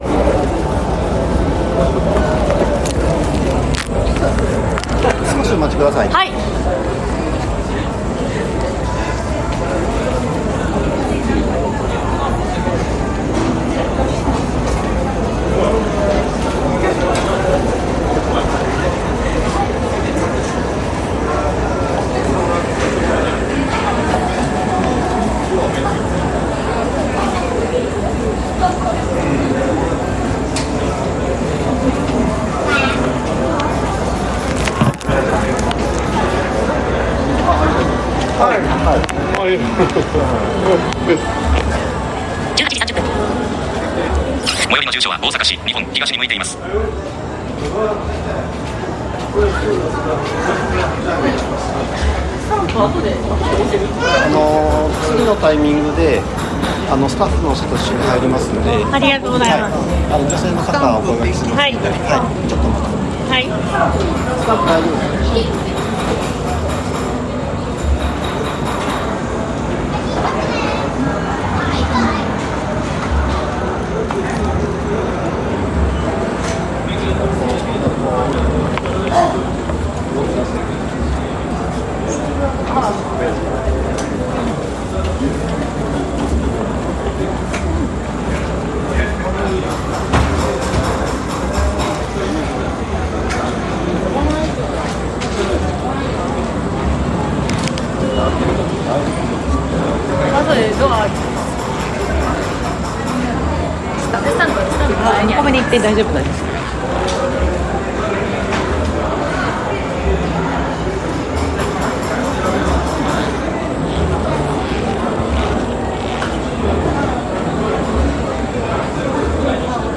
お兄さんが案内してくれました。ノーカット、ノー編集ですので、余計なものも入っていますが聞いてください。